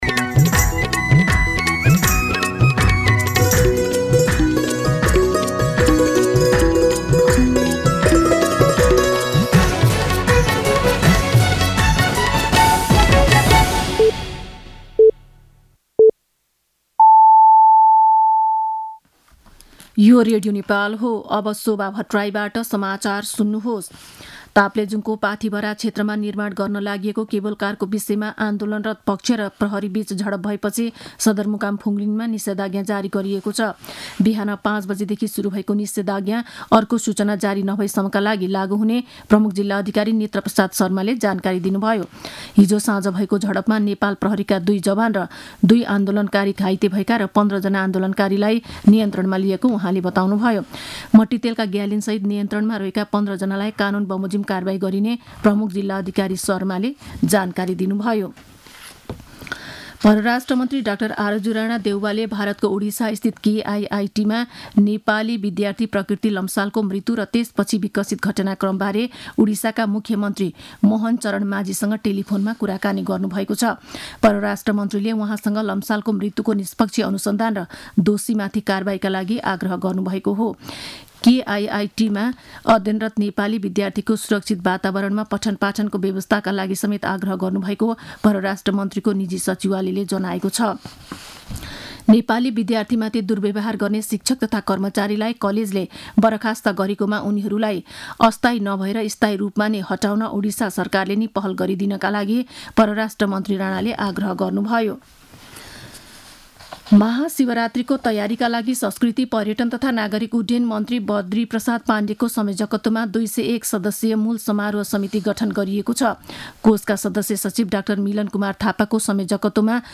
मध्यान्ह १२ बजेको नेपाली समाचार : १२ फागुन , २०८१
12-pm-Nepali-News-1.mp3